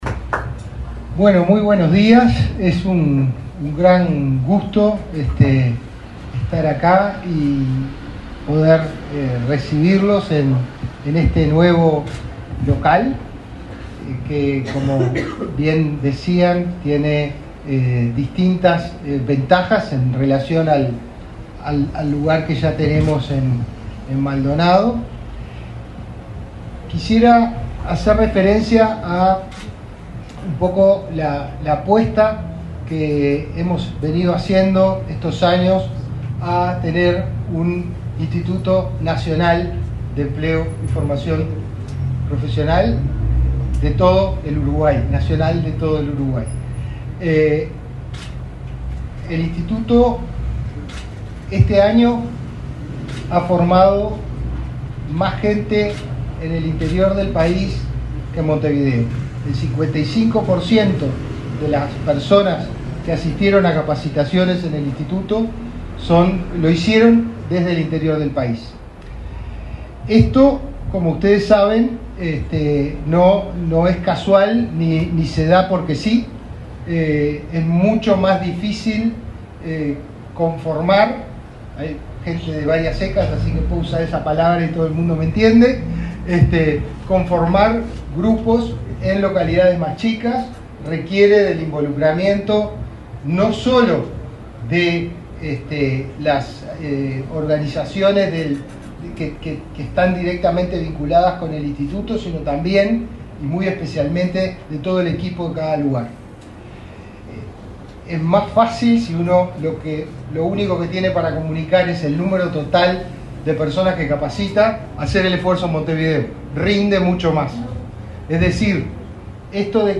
Palabras del director del Inefop, Pablo Darscht
Palabras del director del Inefop, Pablo Darscht 20/12/2024 Compartir Facebook X Copiar enlace WhatsApp LinkedIn El director del Instituto Nacional de Empleo y Formación Profesional (Inefop), Pablo Darscht, encabezó el acto de inauguración de la sede del organismo en Maldonado.